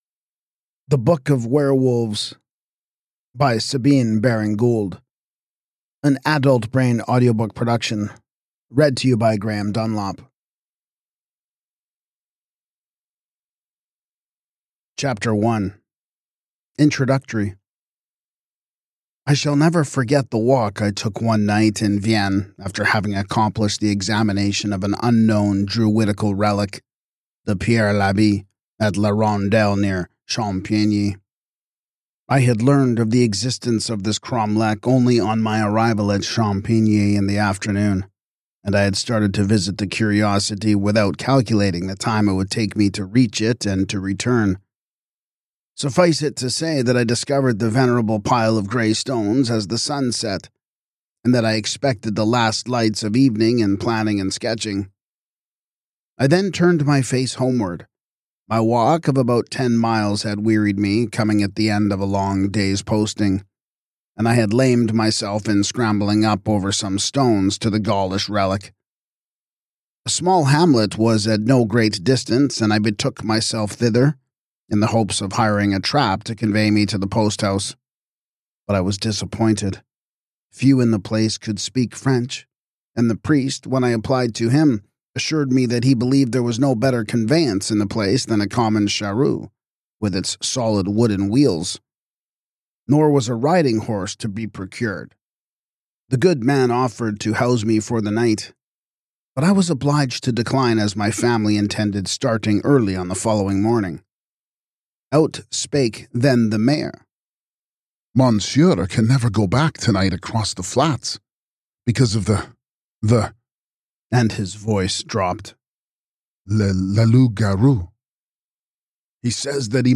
This audiobook is not just for horror fans.